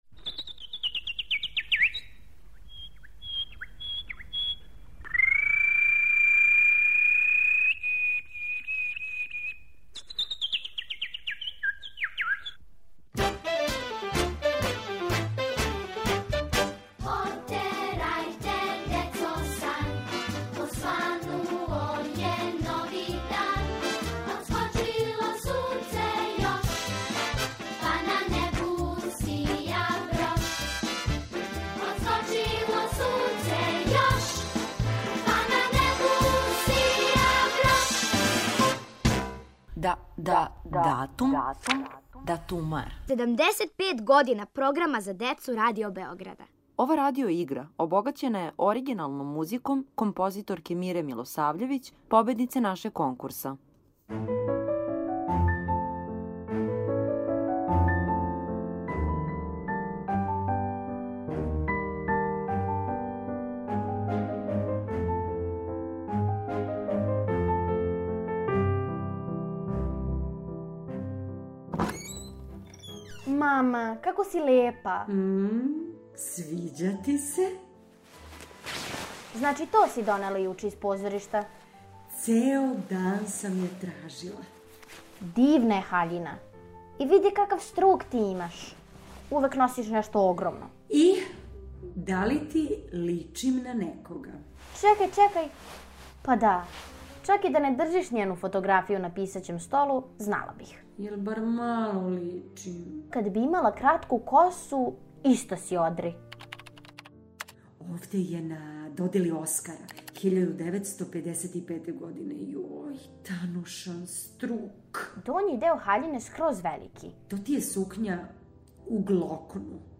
Поводом 75 година Програма за децу и младе Радио Београда, будимо вас другим делом радио игре, обогаћене музиком композиторке Мире Милосављевић, победнице нашег конкурса.